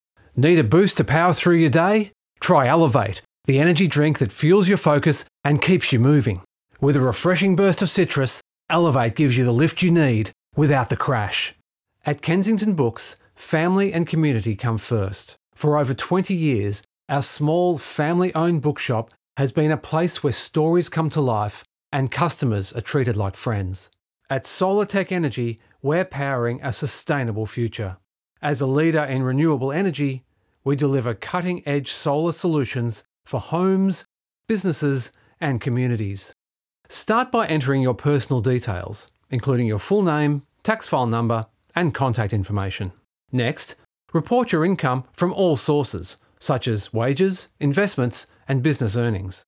My voice is warm, clear, and approachable. Great for believable, conversational reads.